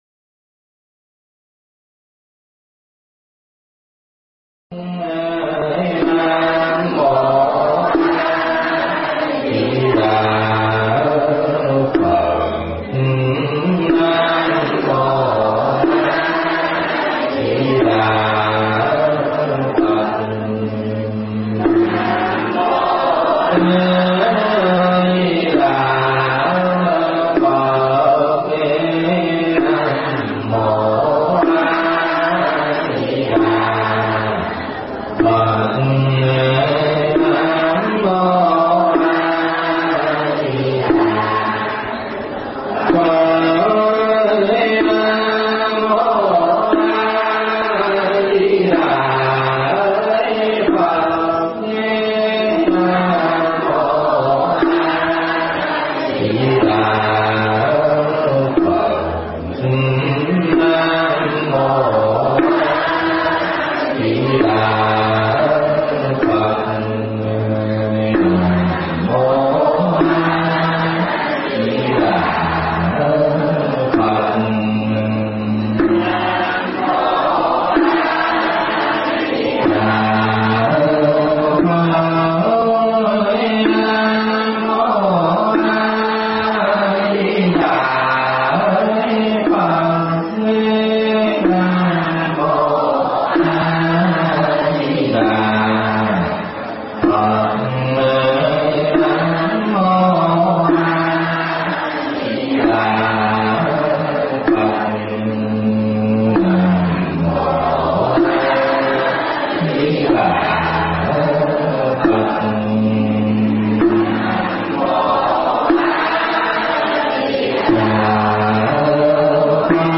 Tải mp3 Pháp Thoại Để Được Chiến Thắng Sau Cùng
giảng tại Chùa Phước Linh